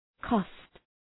cost Προφορά
{kɒst}